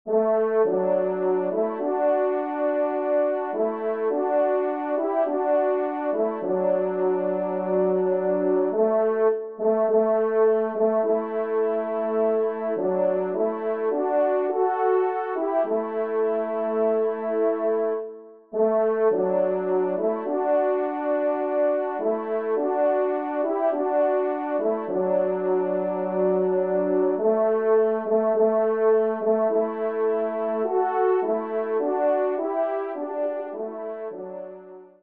Genre :  Divertissement pour Trompes ou Cors
2ème Trompe